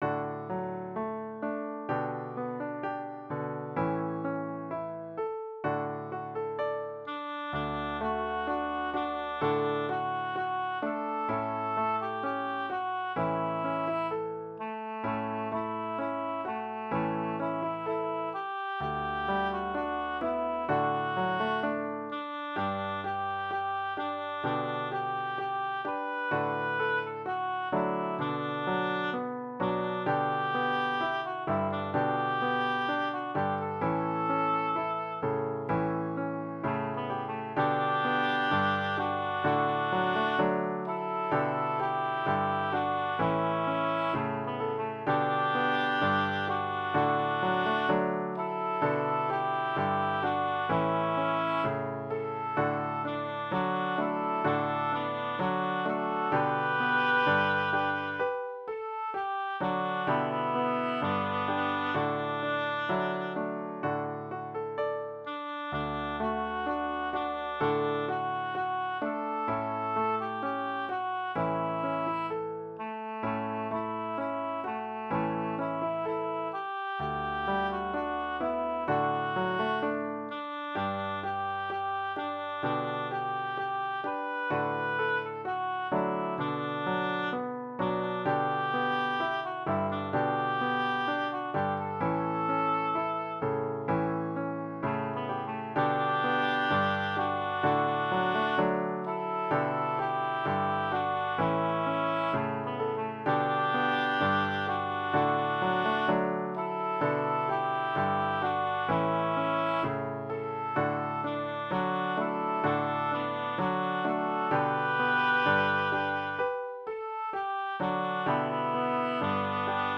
Voicing/Instrumentation: Duet , Vocal Solo
EFY style/Contemporary